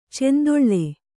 ♪ cendoḷḷe